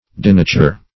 Denature \De*na"ture\, v. i.